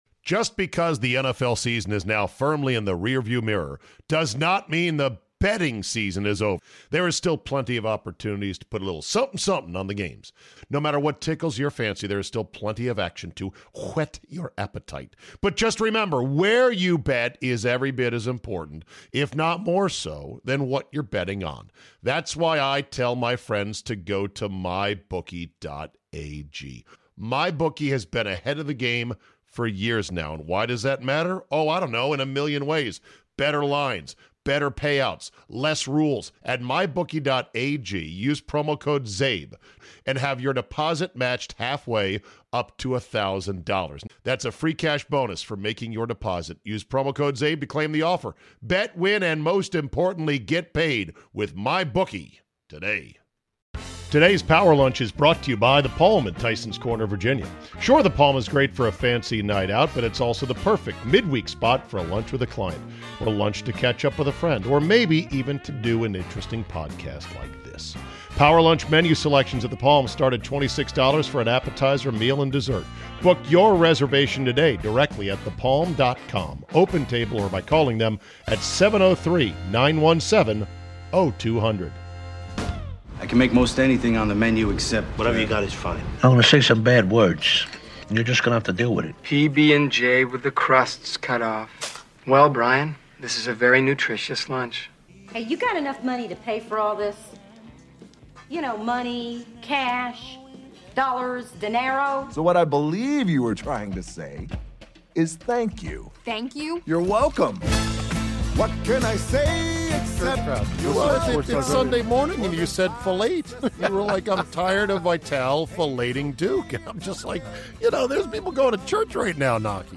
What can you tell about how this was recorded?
The Palm steakhouse in Tysons Corner, VA